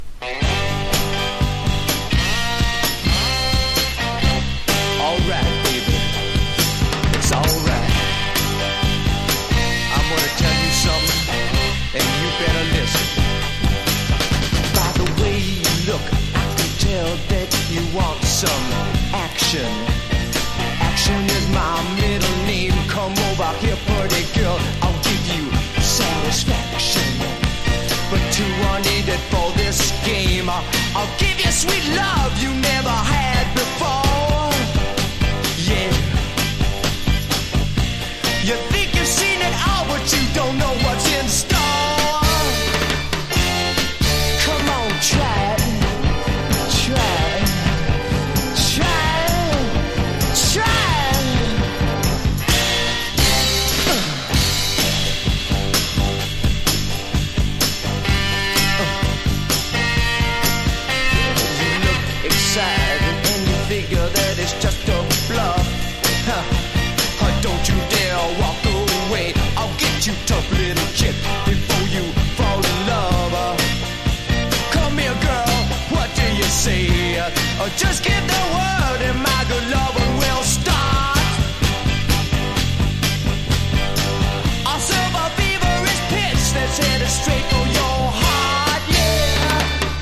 1. 60'S ROCK >
BLUES ROCK / SWAMP